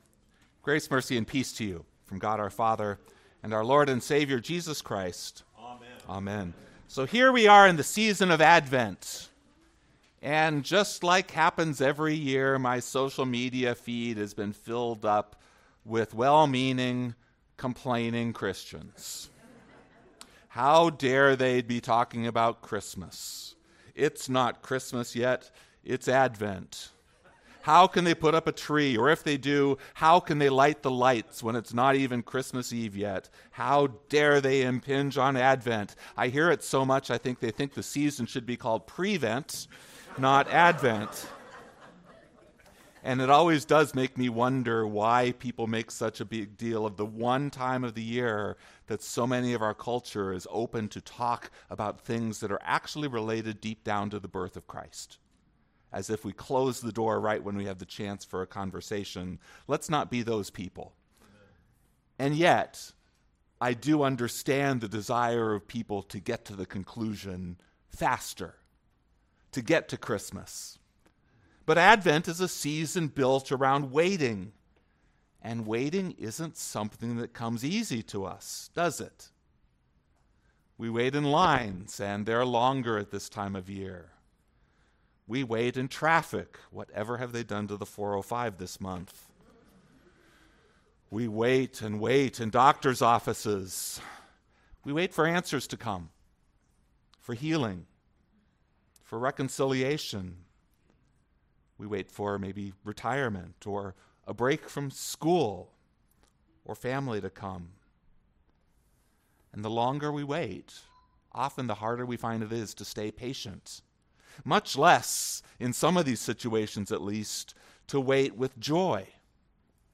Wednesday Sermon